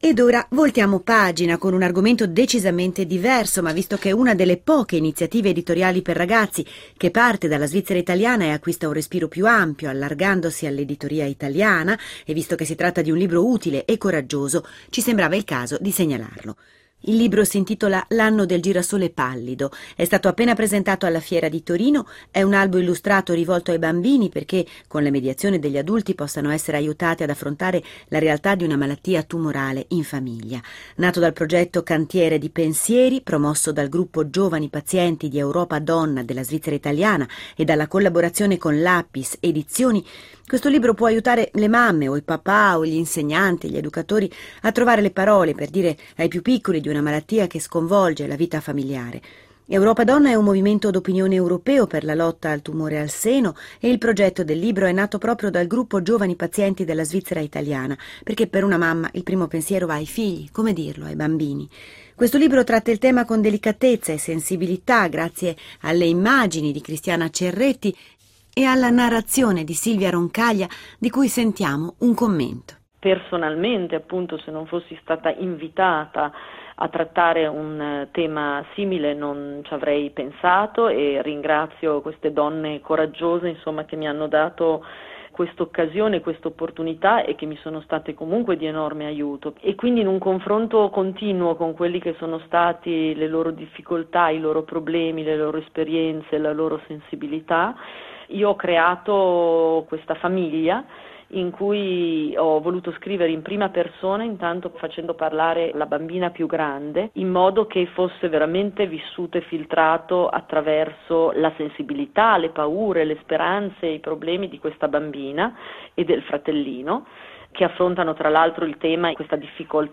Intervista sul libro “L’anno del girasole pallido” da Geronimo della RadioTelevisioneSvizzera